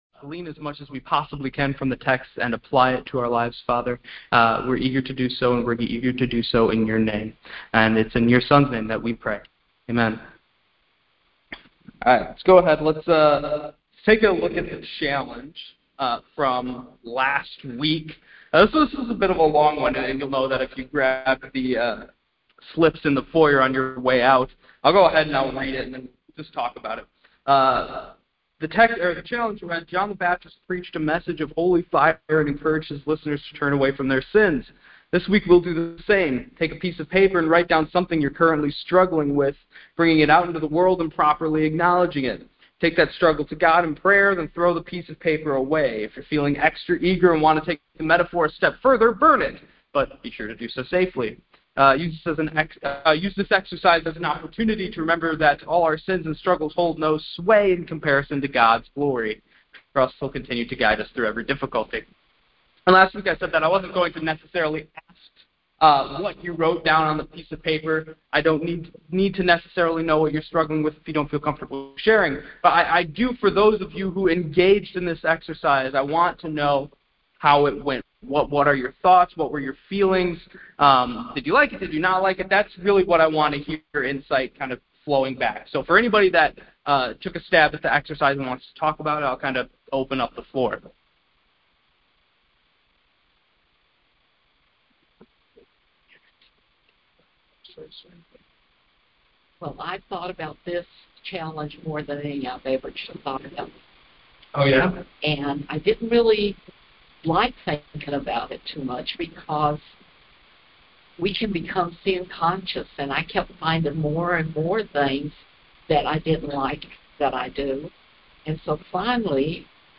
Bible Study 2.4.26